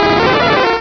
Cri de Rapasdepic dans Pokémon Rubis et Saphir.